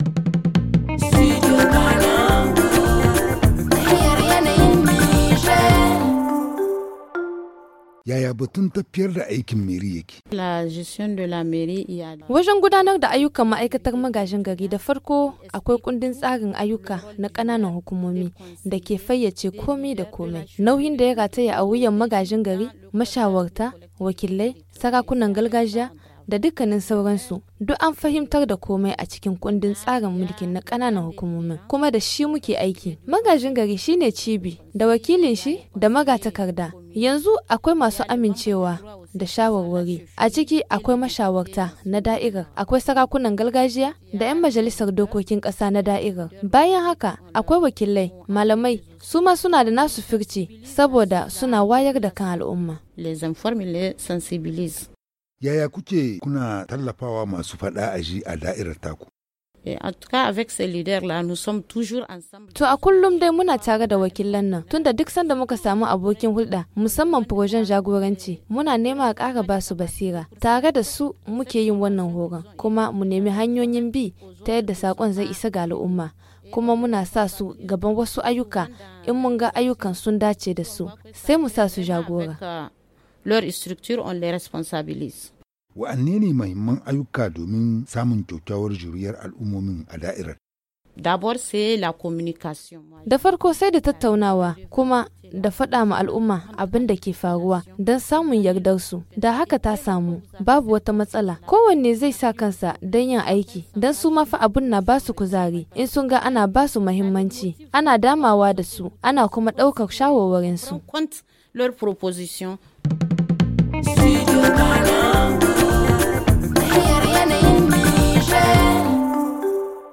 Le magazine en haoussa